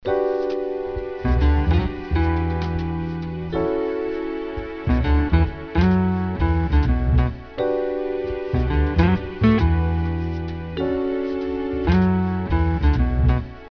washing.WAV